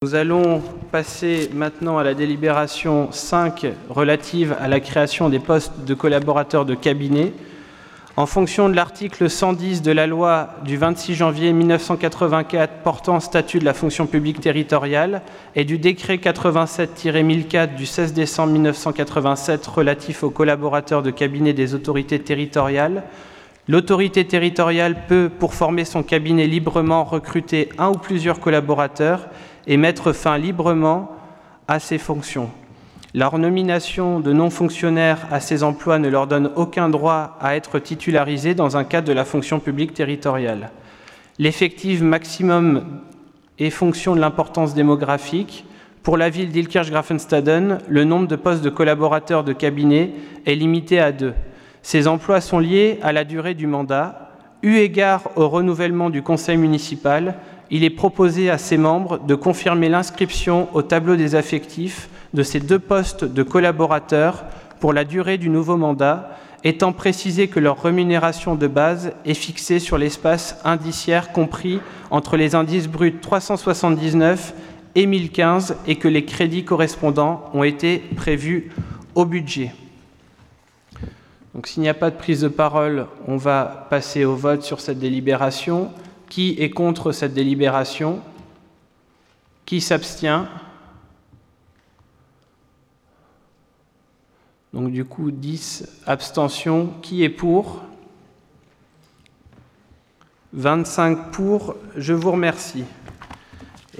Point 5 : Postes de collaborateurs de cabinet Conseil Municipal du 04 juillet 2020